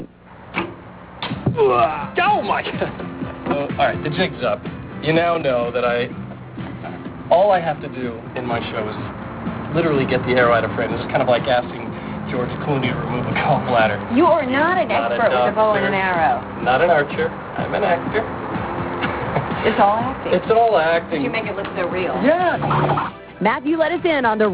Here is a couple of clips from an interview he did with Access Hollywood.